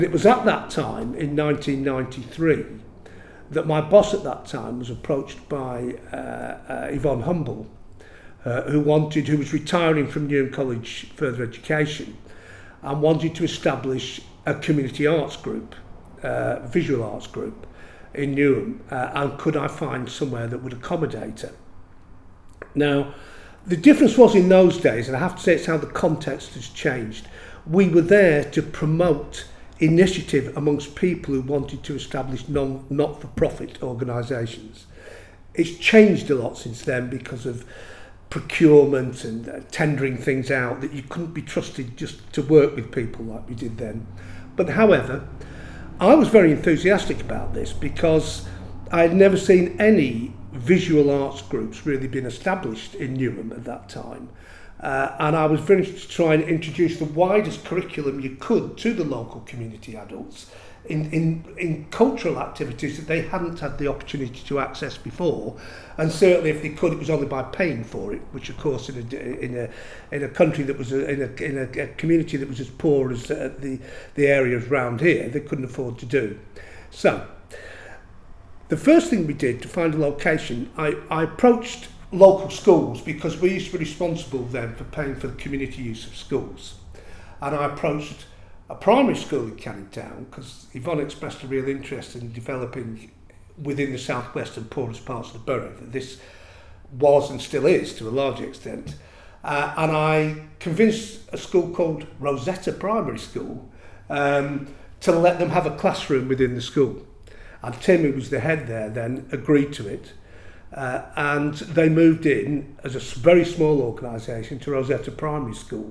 INT: Interviewer